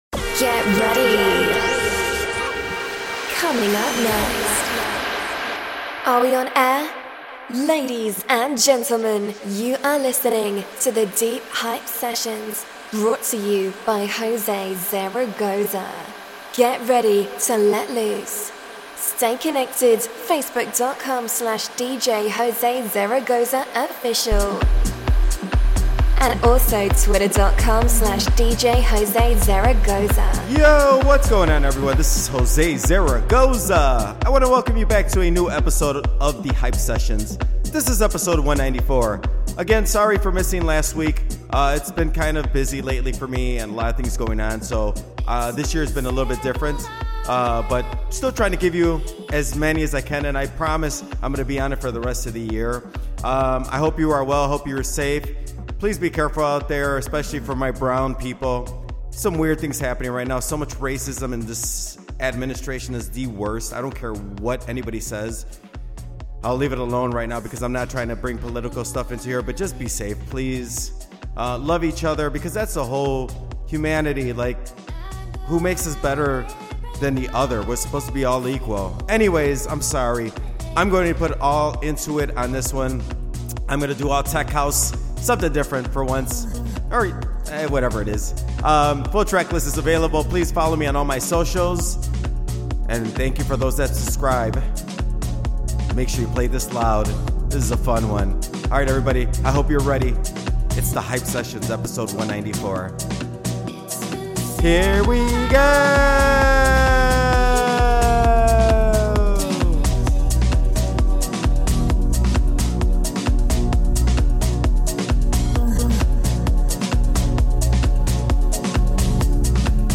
The latest show is out, and it's full of tech house!